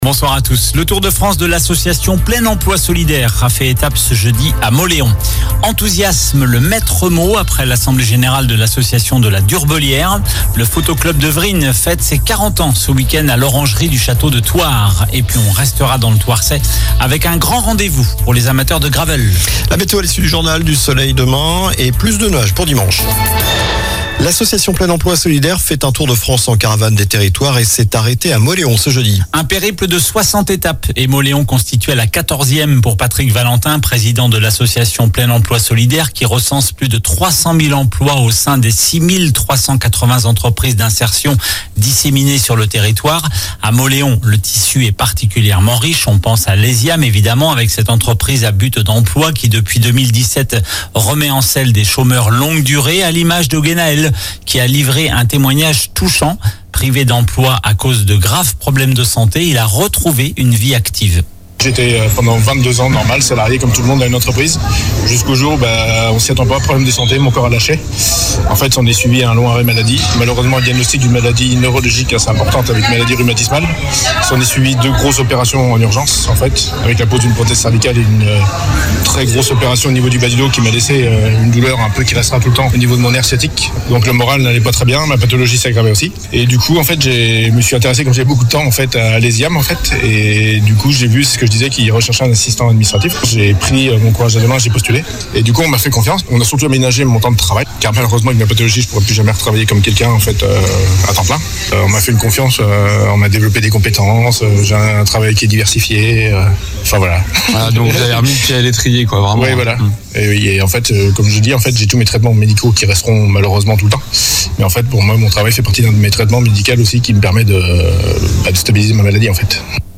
Journal du vendredi 19 avril (soir)